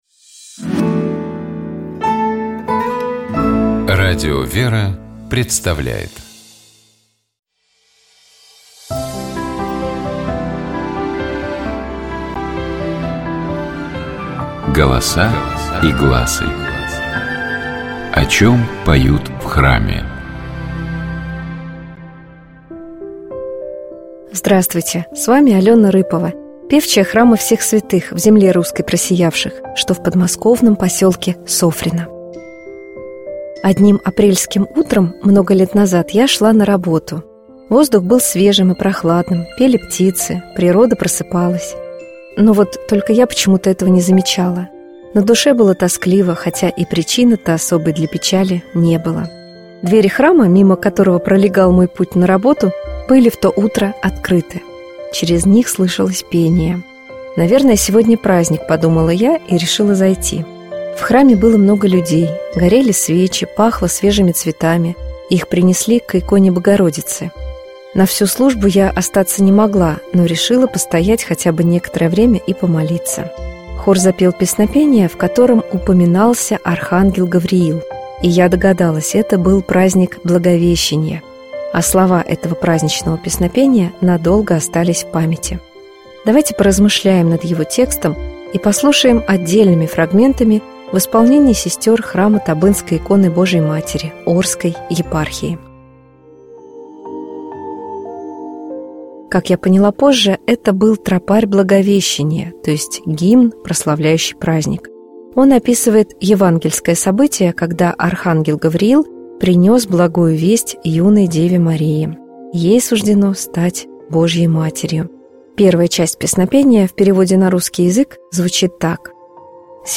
Как я поняла позже, это был тропарь Благовещения — то есть гимн, прославляющий праздник. Он описывает евангельское событие, когда Архангел Гавриил принёс благую весть юной Деве Марии.
Давайте послушаем тропарь Благовещения полностью в исполнении сестёр храма Табынской иконы Божией Матери.